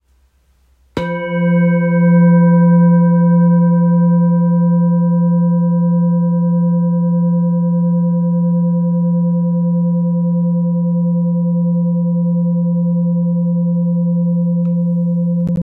The rippled texture enhances the bowl’s resonance, producing rich, layered tones that are soothing, immersive, and full of harmonic depth.
Lightweight and easy to hold, it responds beautifully to gentle strikes or rim-rubbing techniques, creating a calm, grounding sound that fills the space.
Heart-bowl-mallet.m4a